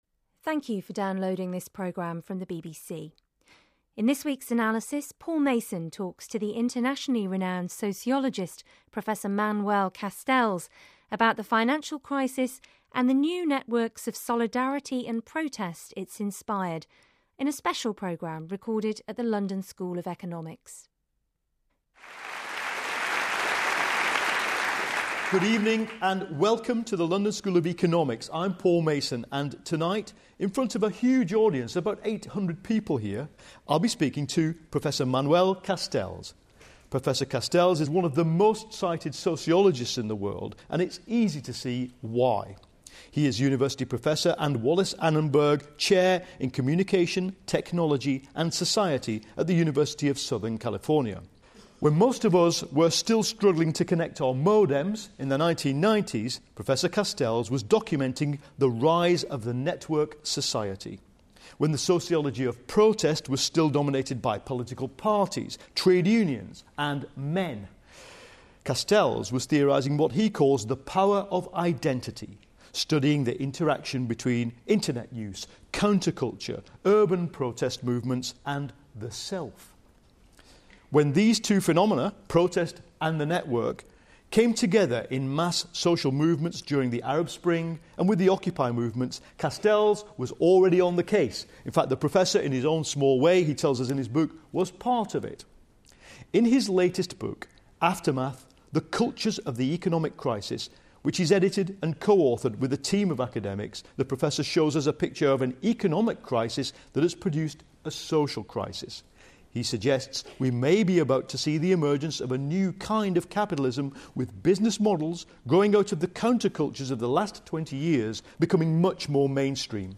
Newsnight economics editor Paul Mason interviewed Prof Castells in front of an audience at The London School of Economics for BBC Radio 4's Analysis about his latest book Aftermath: The Cultures of the Economic Crisis. Prof Castells suggests we may be about to see the emergence of a new kind of capitalism, with businesses growing out of the counter-cultures of the last 20 years.